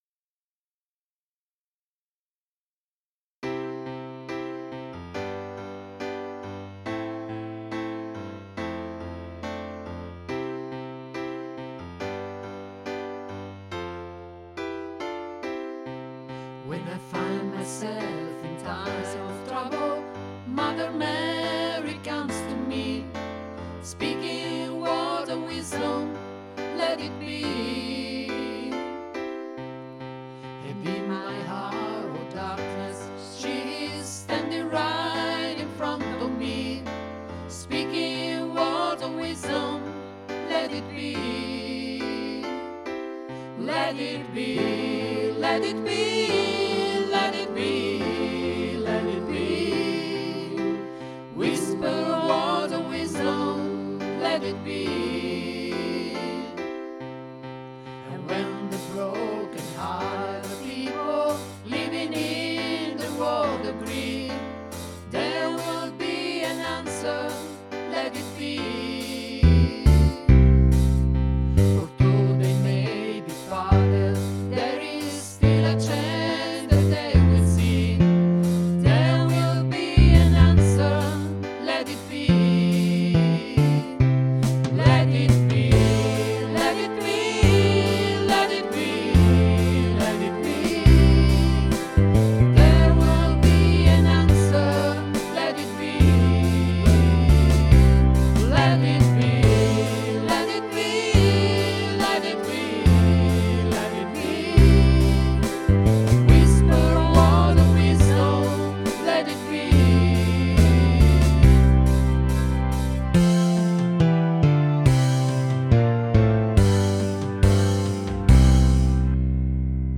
Traccia Bassi